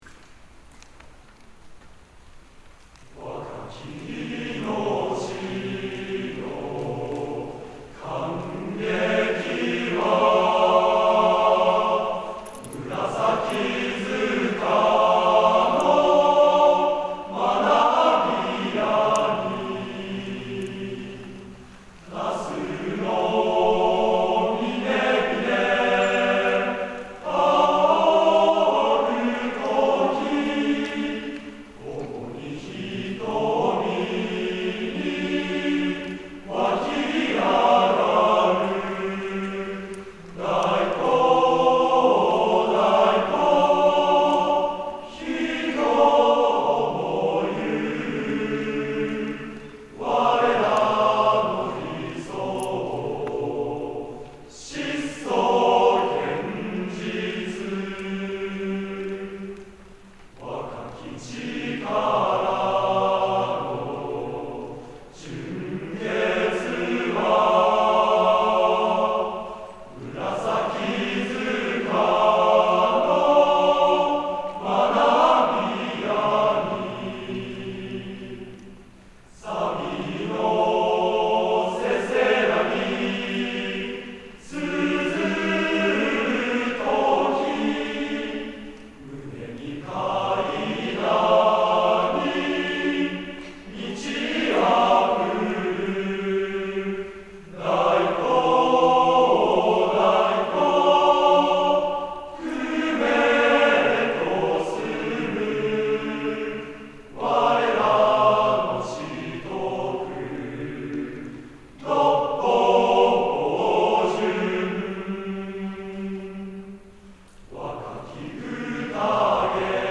定期演奏会～合唱部・吹奏楽部による男声二部合唱